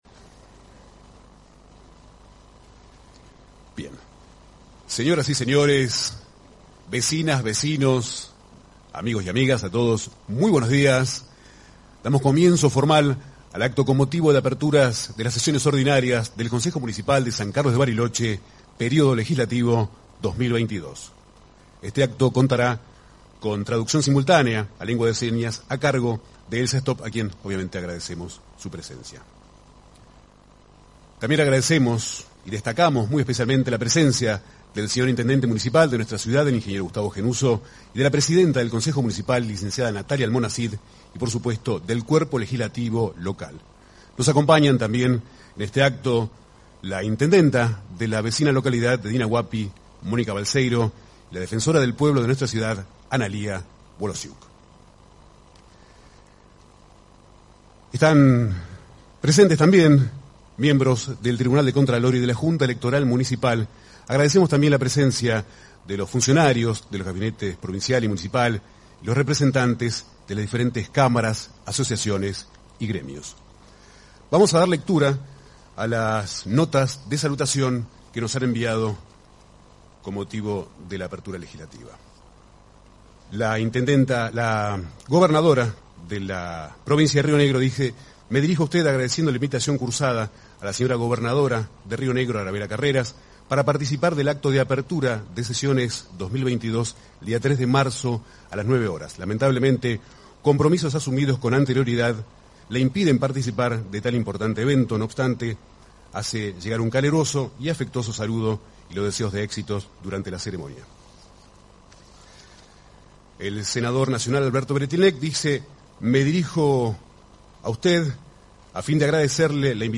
Hora de inicio: 9:15 h. Carácter de la Sesión: ordinaria.
Posición de audio: 00h 07m 18s: El Intendente expone su informe anual y da apertura formal al año legislativo 2022.